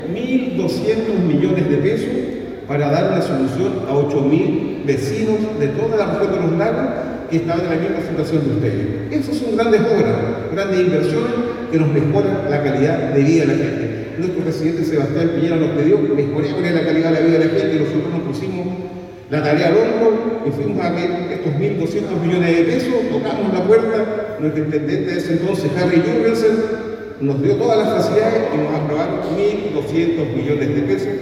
En una ceremonia realizada en el recinto Sago de la ciudad de Osorno, el seremi de Bienes Nacionales, Jorge Moreno, hizo entrega de 81 títulos de dominio a vecinos de la provincia de Osorno.
El aporte del Gobierno regional fue de $1.200 millones, los que permitirá solucionar los problemas de 8 mil vecinos de la región de Los Lagos, finalizó diciendo el Secretario Regional Ministerial a las familias presente en la ceremonia.